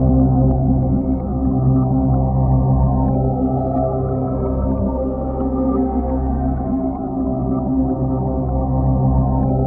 Index of /90_sSampleCDs/Spectrasonic Distorted Reality 2/Partition G/01 DRONES 1
GURGLE.wav